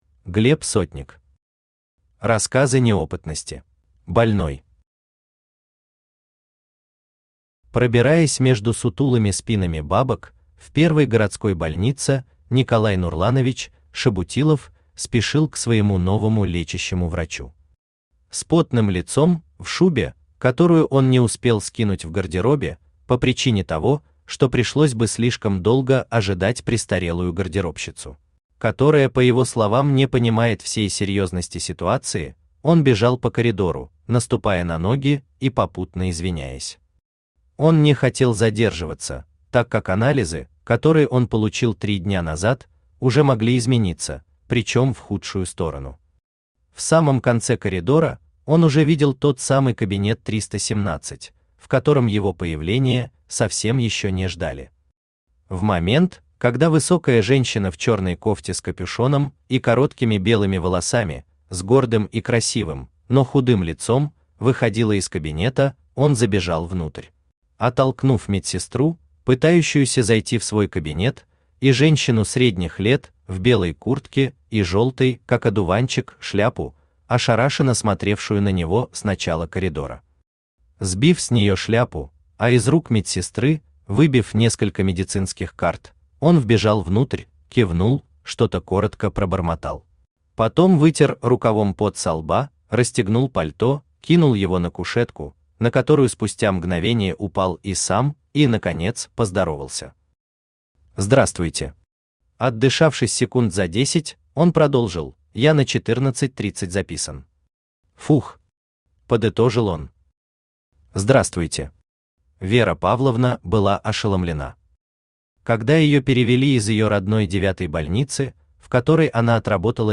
Аудиокнига Рассказы неопытности | Библиотека аудиокниг
Aудиокнига Рассказы неопытности Автор Глеб Дмитриевич Сотник Читает аудиокнигу Авточтец ЛитРес.